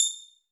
Techmino/media/effect/chiptune/click.ogg at 3226c0c831ec9babe3db1c1e9db3e9edbe00a764
click.ogg